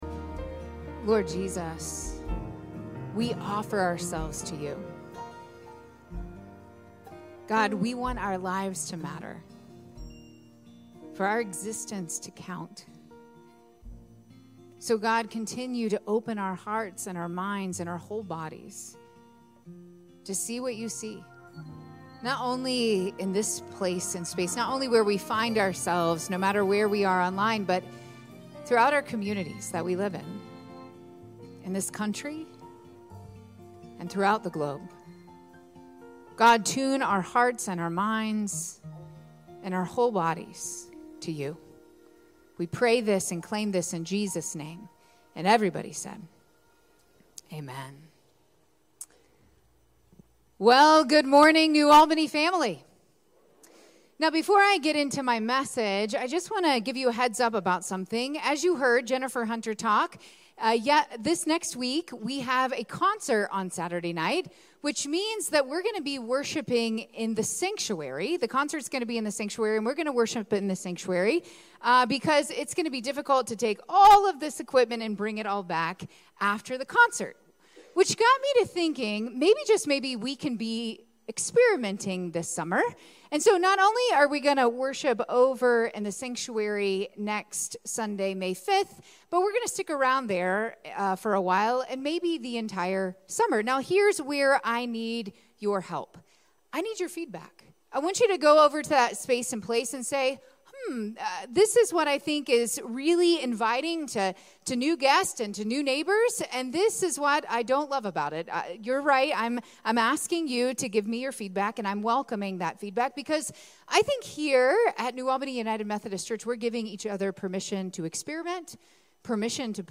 April 28, 2024 Sermon